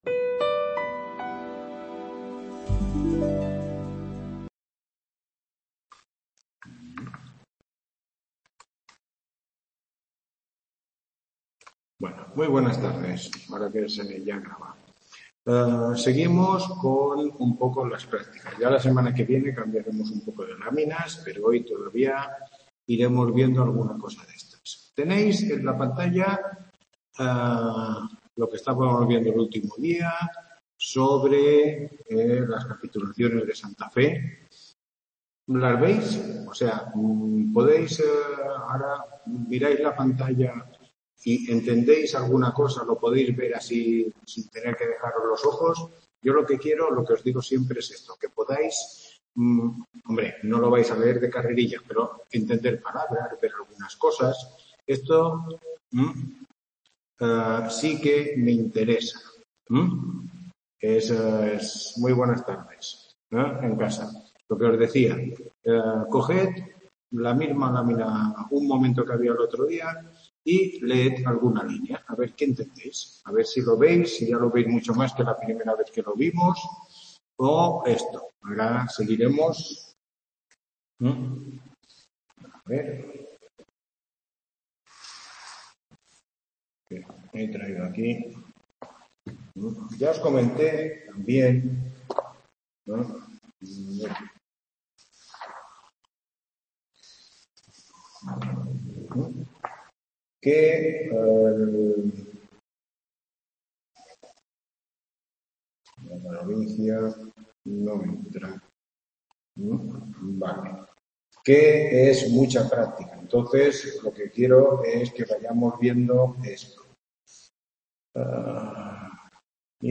Tutoría 9